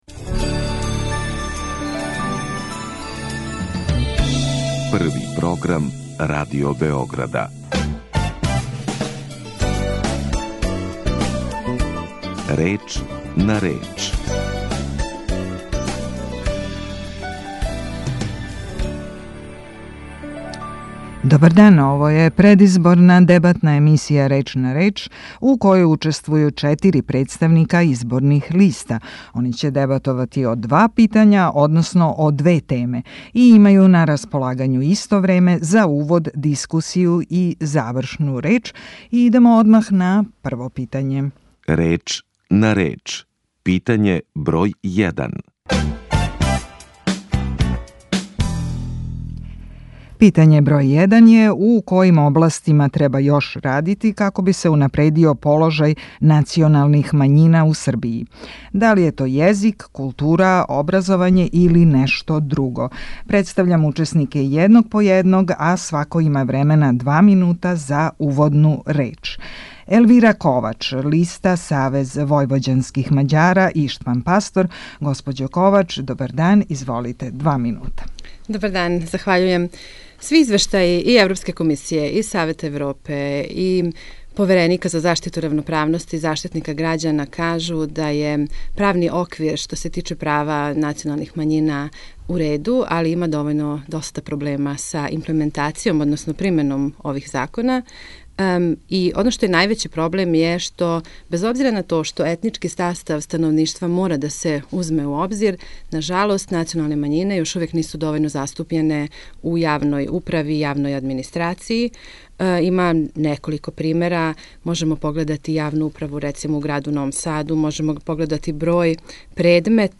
Разговор у емисији одвија се према унапред одређеним правилима тако да учесници имају на располагању исто време за увод, дискусију и завршну реч.